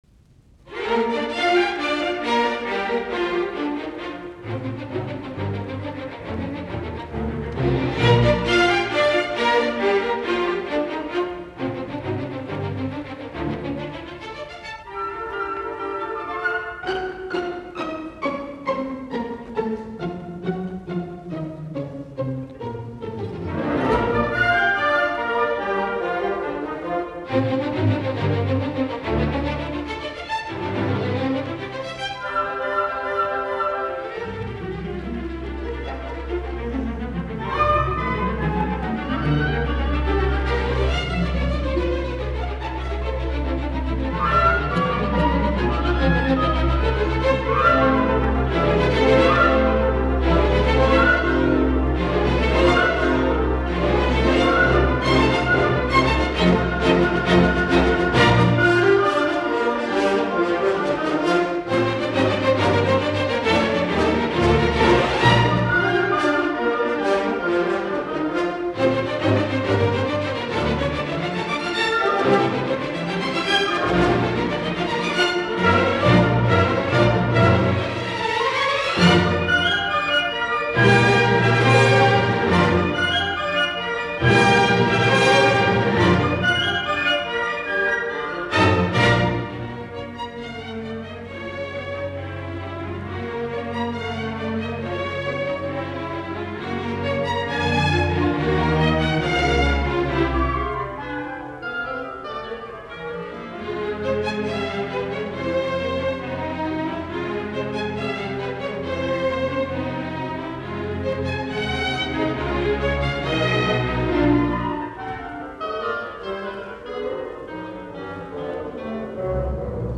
Allegro con moto